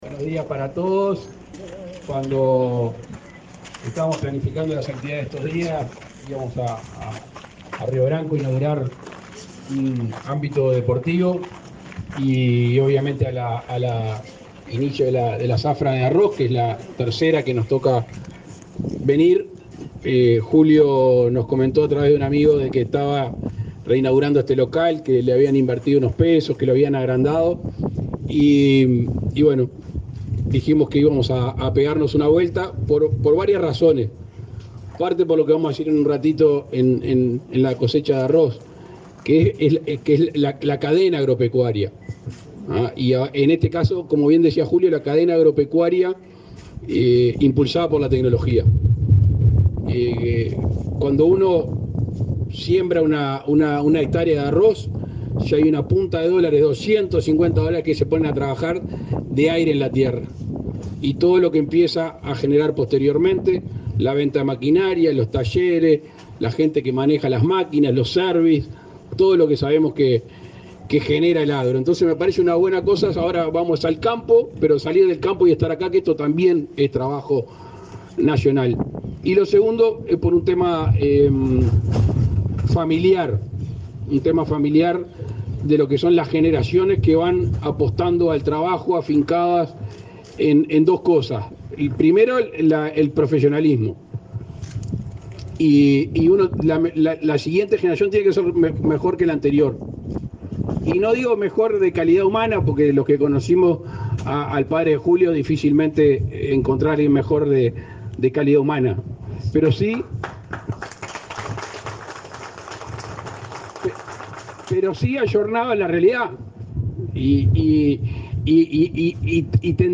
Palabras del presidente Lacalle Pou en inauguración de sucursal de Interagrovial
El presidente de la República. Luis Lacalle Pou, participó en la inauguración de la sucursal de la empresa Interagrovial en Río Branco, este 18 de